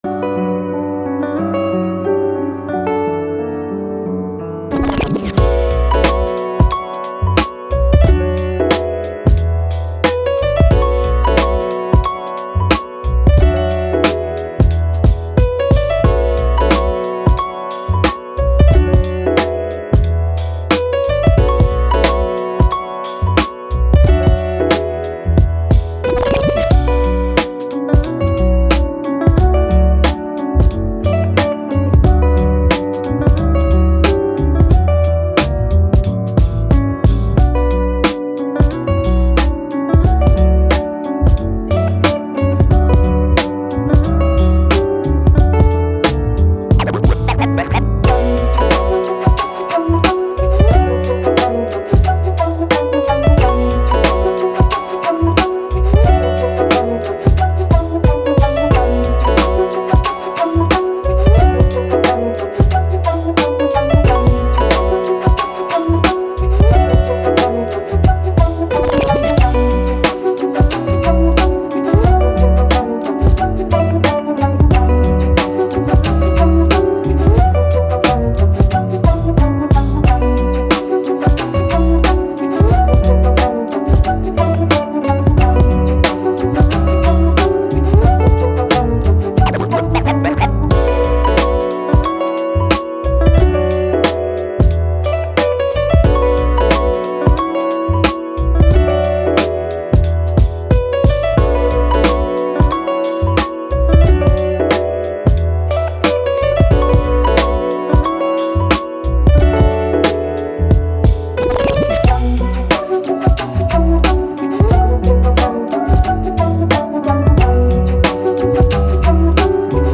Hip Hop, Upbeat, Flute, Positive